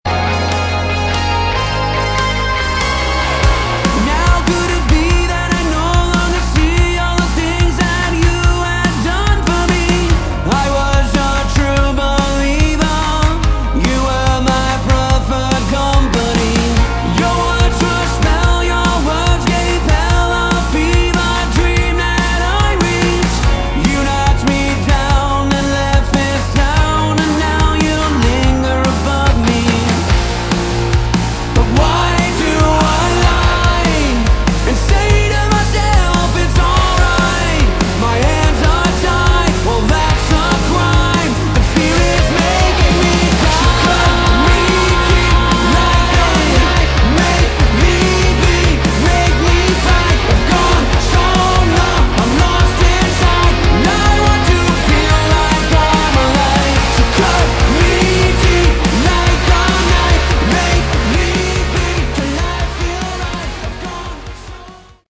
Category: AOR
Vocals, Guitars, Synths
Drums, Percussion